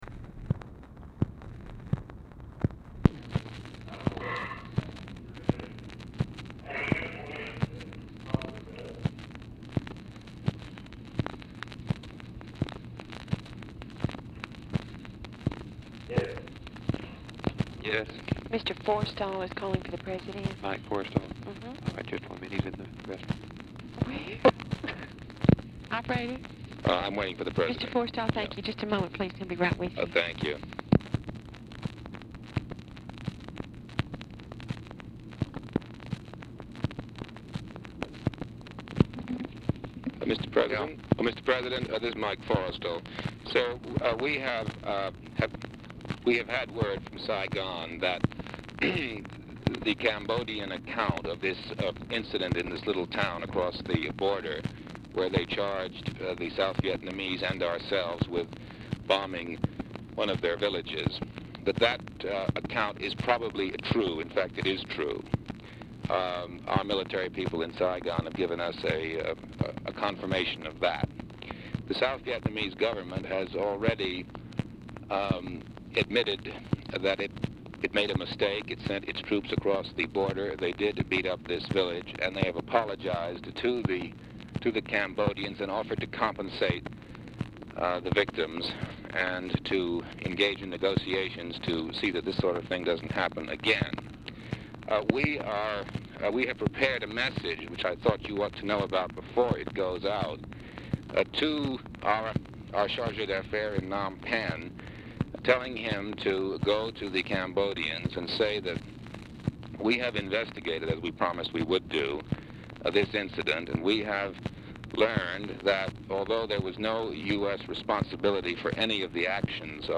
Telephone conversation # 2603, sound recording, LBJ and MIKE FORRESTAL, 3/21/1964, 5:21PM | Discover LBJ
MOYERS? TELLS SECRETARY LBJ WILL BE ON THE LINE IN A MINUTE; FORRESTAL ON HOLD 0:40
Format Dictation belt
Location Of Speaker 1 Oval Office or unknown location
Specific Item Type Telephone conversation